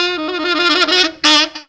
ROOT TRILL.wav